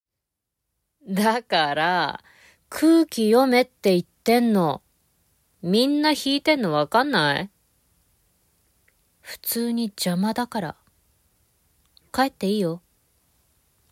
ボイスサンプル
セリフA